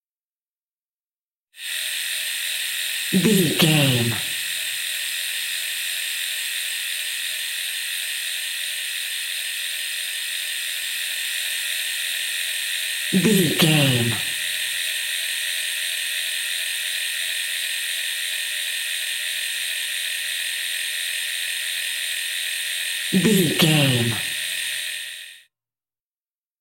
Electric shaver small flat
Sound Effects